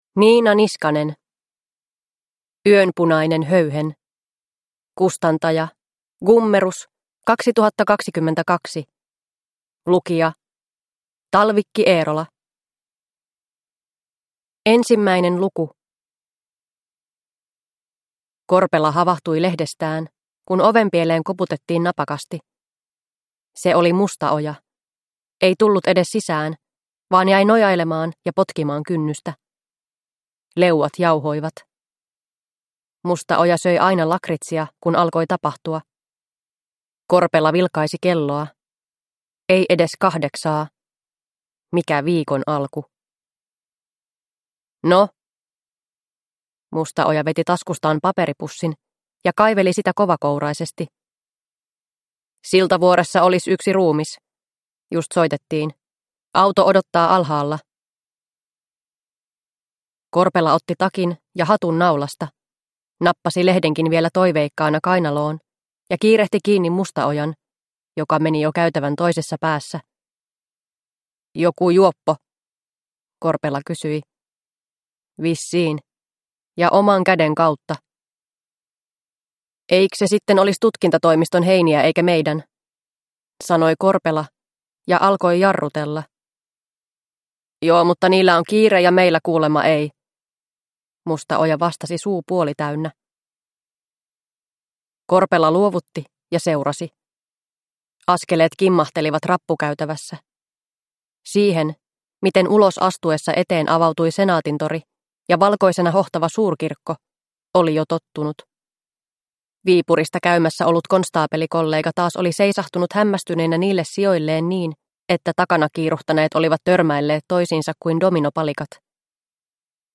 Yönpunainen höyhen – Ljudbok